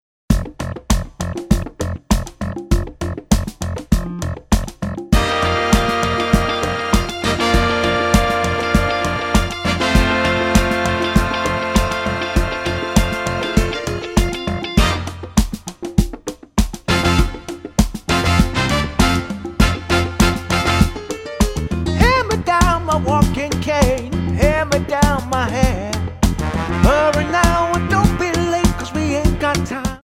--> MP3 Demo abspielen...
Tonart:F Multifile (kein Sofortdownload.